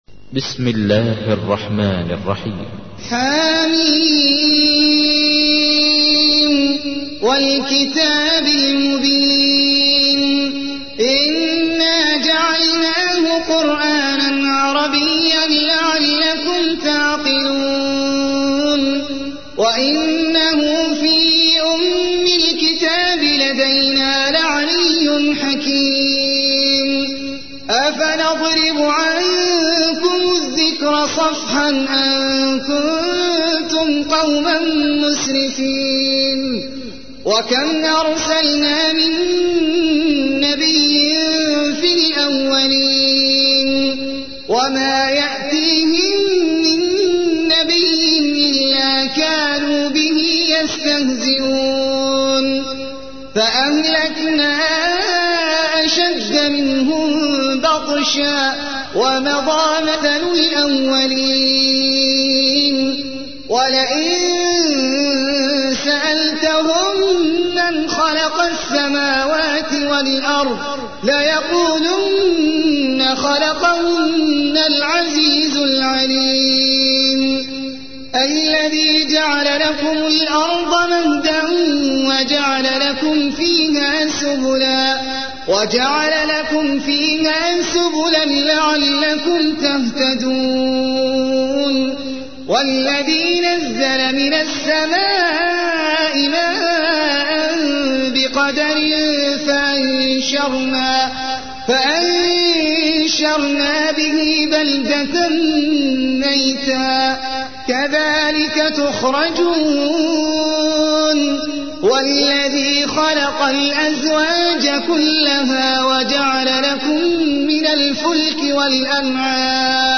تحميل : 43. سورة الزخرف / القارئ احمد العجمي / القرآن الكريم / موقع يا حسين